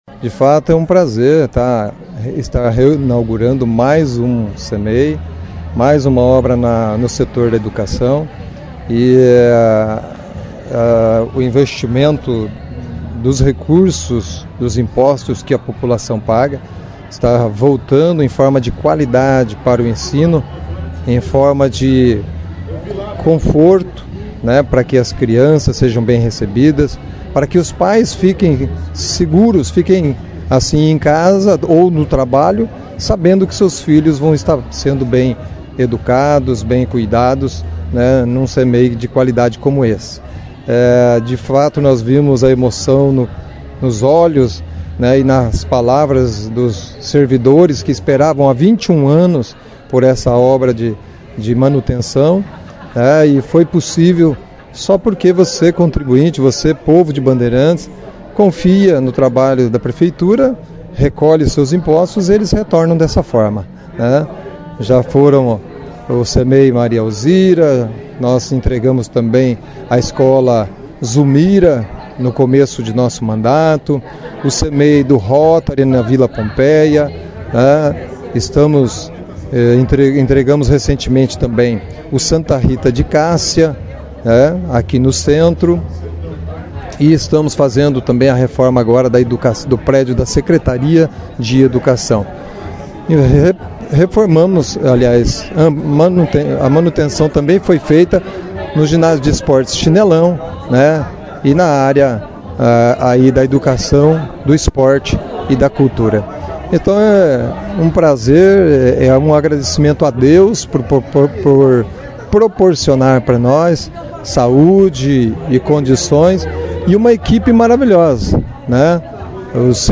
Segundo administração o investimento de mais de 600 mil reais, está sendo uma reforma completa. A entrega foi destaque da 1ª edição do jornal Operação Cidade desta terça-feira, 07, 03, com a participação do prefeito Jaelson Matta, Secretária de Educação e Cultura, professora Nelci Maria Martins de Queiroz